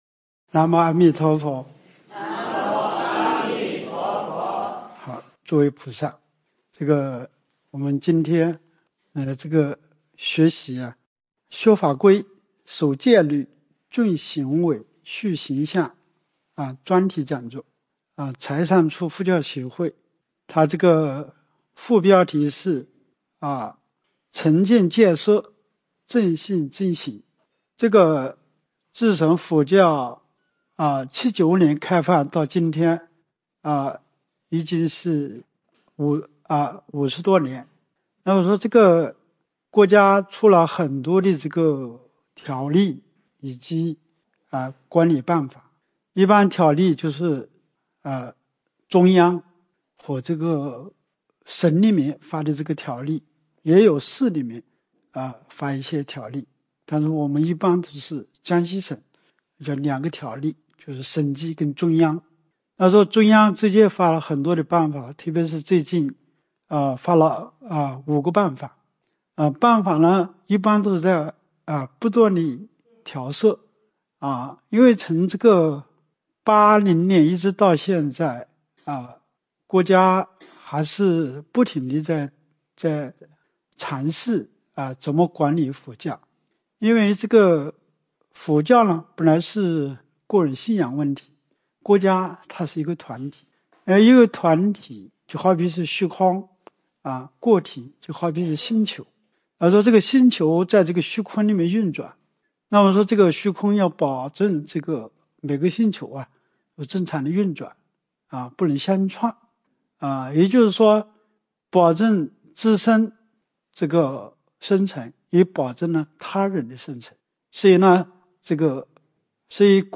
柴桑区佛协会：学法规、守戒律、重修为、树形象专题讲座
崇俭戒奢·正信正行主题宣讲
崇俭戒奢正信正行讲座.mp3